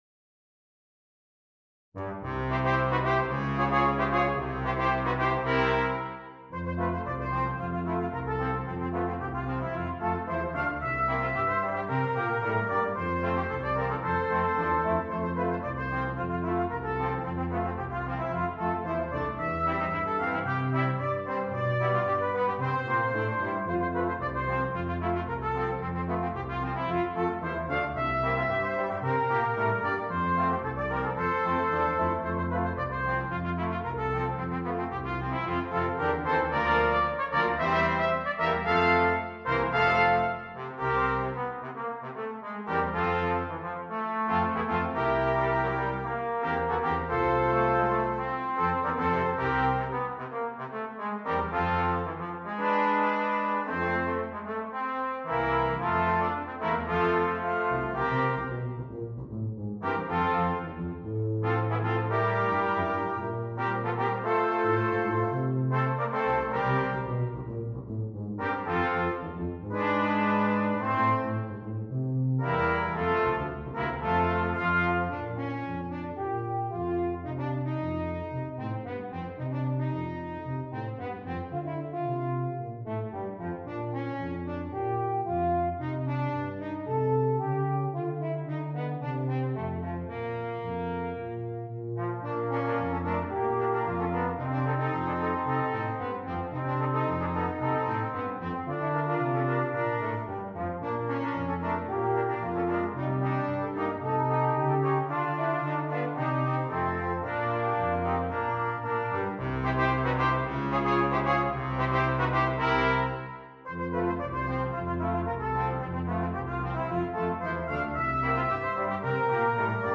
Brass Quintet
for brass quintet